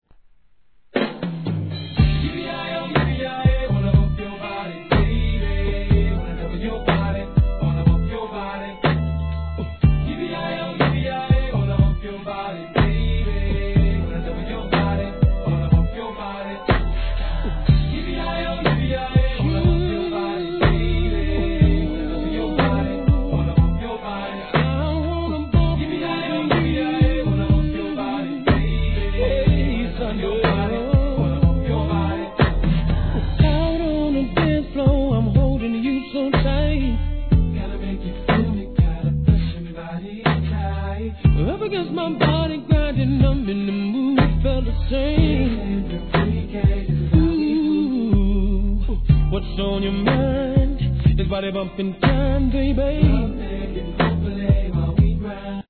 HIP HOP/R&B
やっぱりこういったSLOWで聴かせるナンバーでその実力が分かります!!絶妙なコーラス・ワークでグッと来ます♪